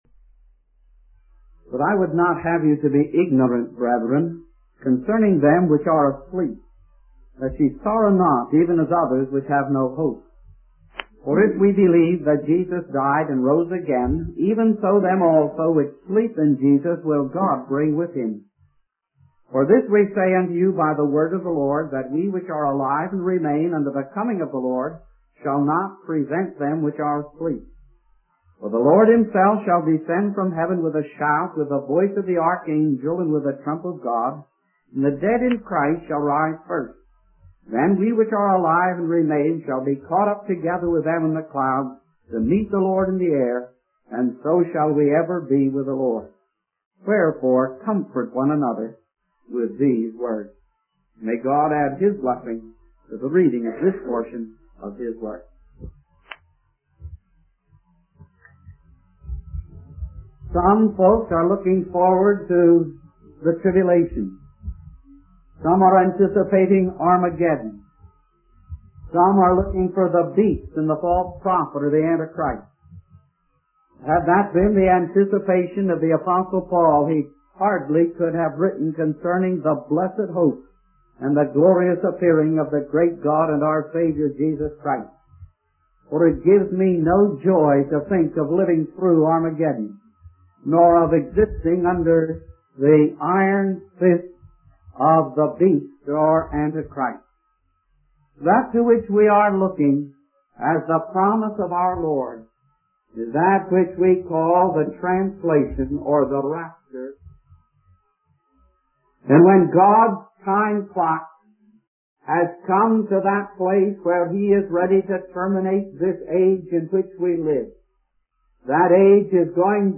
In this sermon, the preacher emphasizes the importance of understanding the doctrine of our Lord's return.